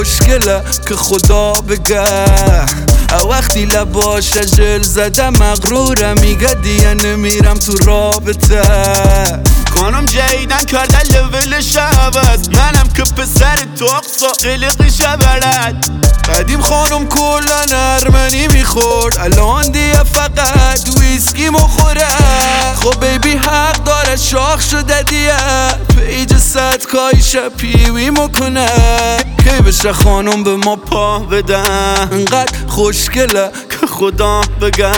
Жанр: Иностранный рэп и хип-хоп / Поп / Рэп и хип-хоп